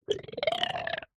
sounds / mob / strider / idle6.ogg